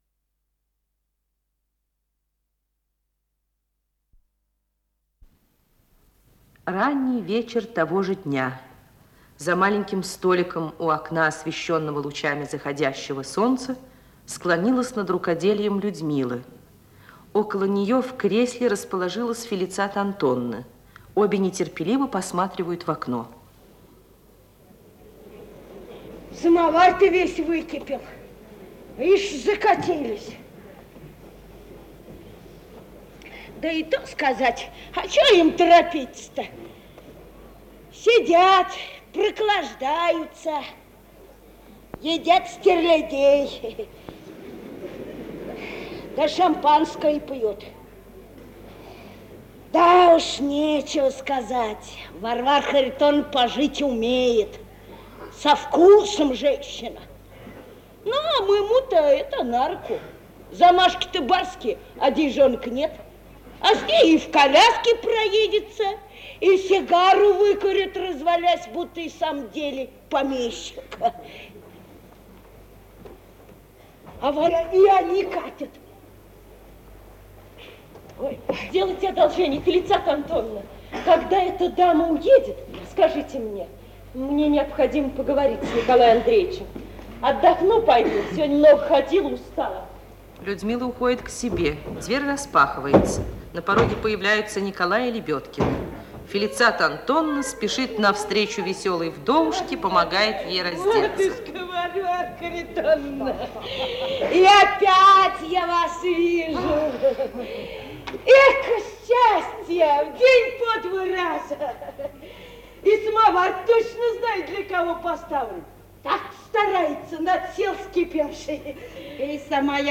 Исполнитель: Артисты МХАТа